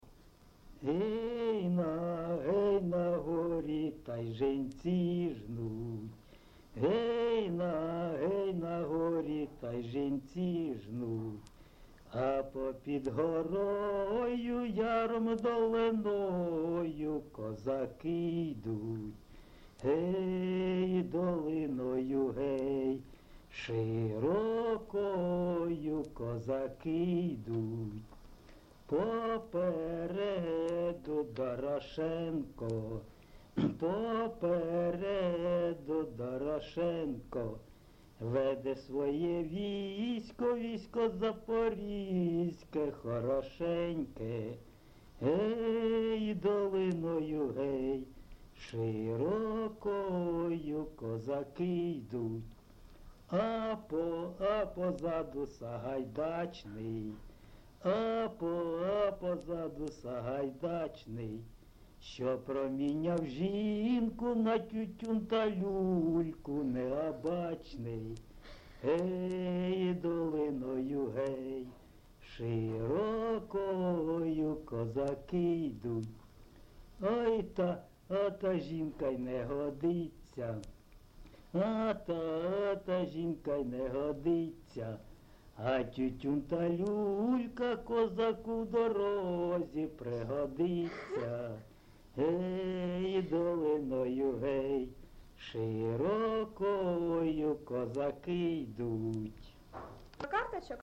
ЖанрКозацькі, Історичні
Місце записум. Дебальцеве, Горлівський район, Донецька обл., Україна, Слобожанщина